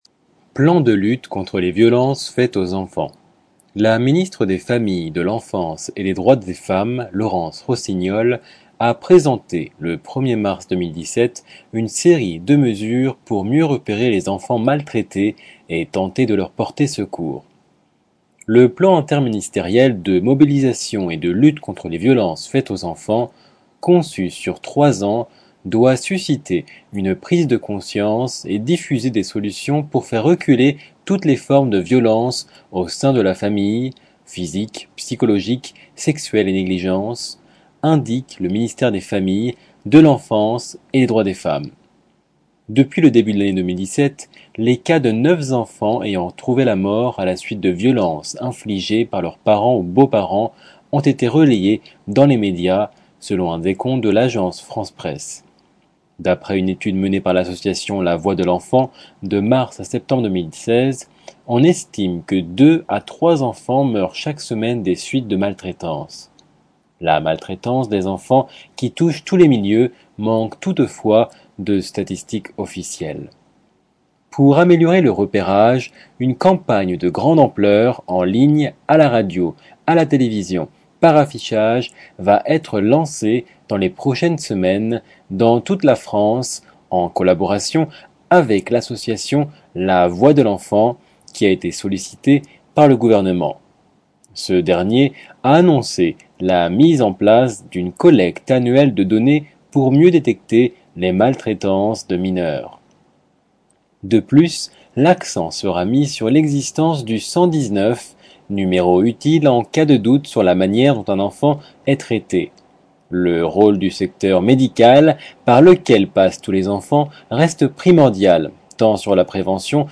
Reportage dans l'unité d'accueil médico-judiciaire pédiatrique du centre hospitalier de Versailles